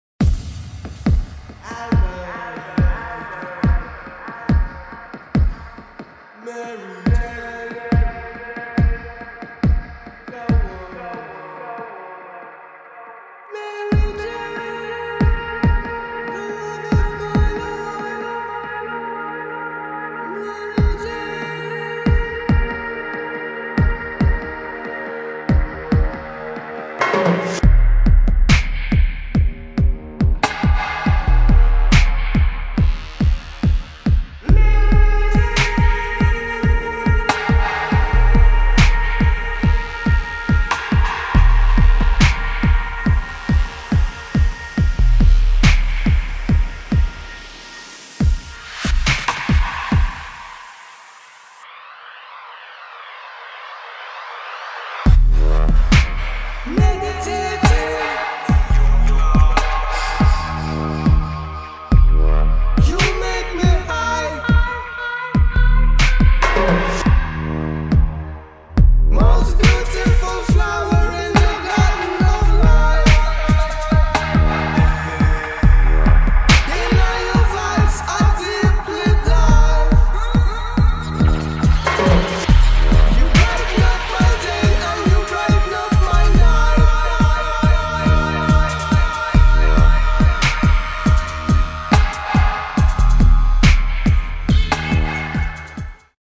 [ DUB | REGGAE | DUBSTEP ]